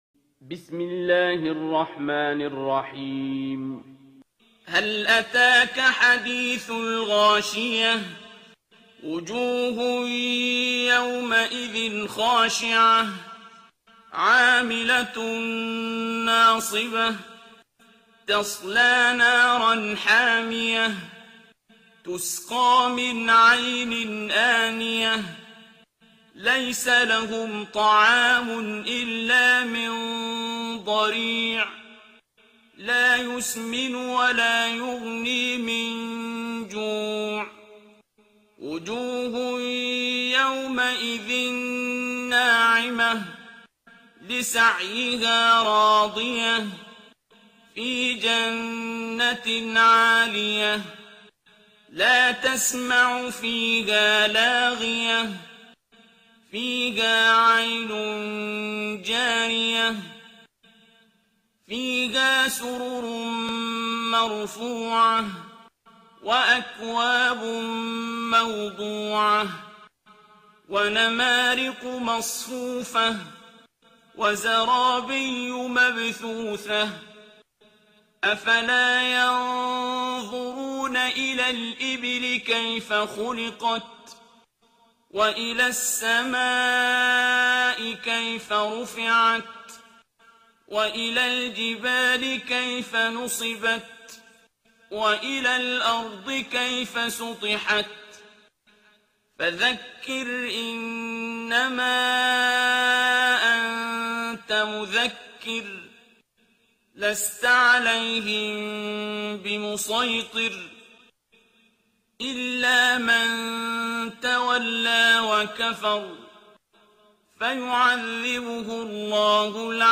ترتیل سوره غاشیه با صدای عبدالباسط عبدالصمد
088-Abdul-Basit-Surah-Al-Ghashiya.mp3